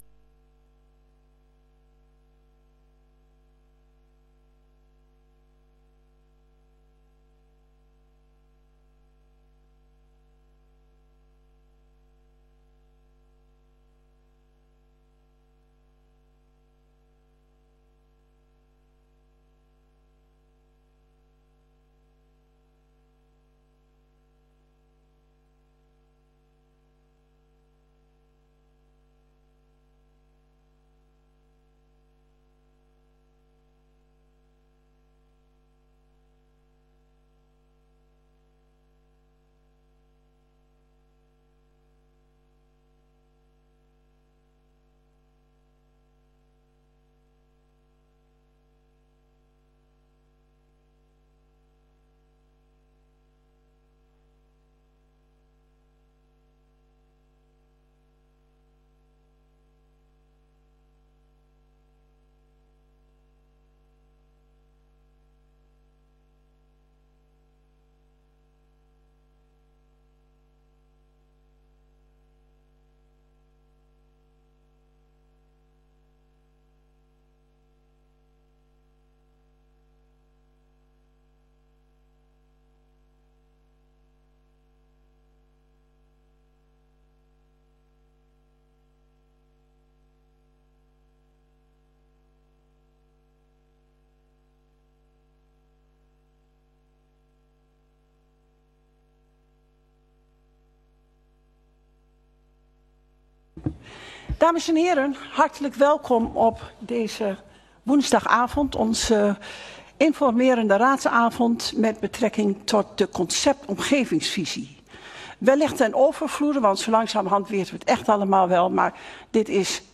Informerende Raadsvergadering Omgevingsvisie.